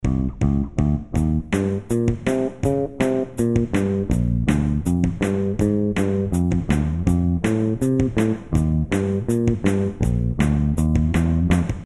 В этом отрывке я сделал только минус.